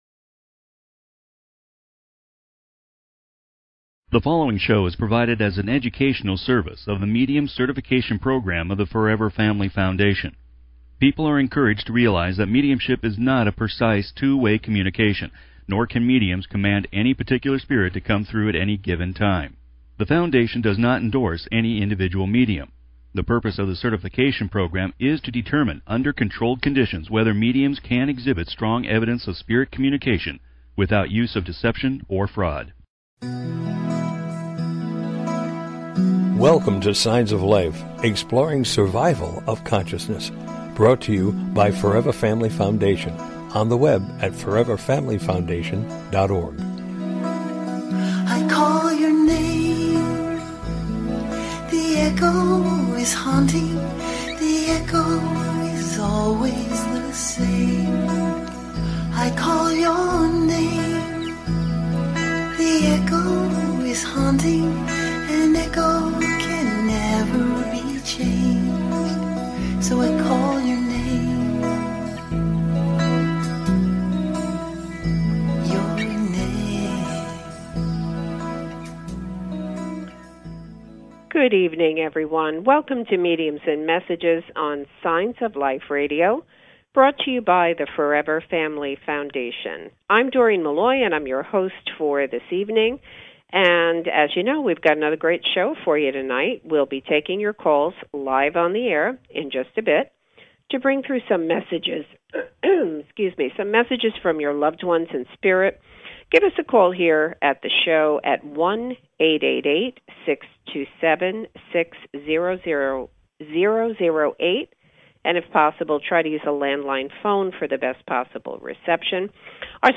Talk Show Episode
Interviewing guest medium